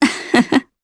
Valance-Vox-Laugh_jp.wav